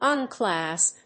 音節un・clasp 発音記号・読み方
/`ʌnklˈæsp(米国英語)/